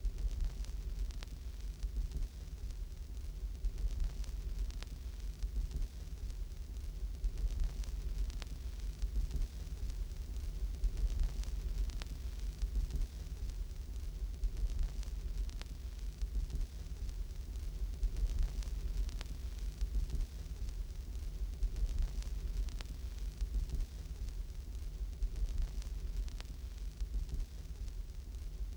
NB: 1920’s recordings were 78rpm, the Freesound sample is probably 33&1/3 or 45rpm. so if you want historical accuracy you’ll have to find a recording of a 78, try here.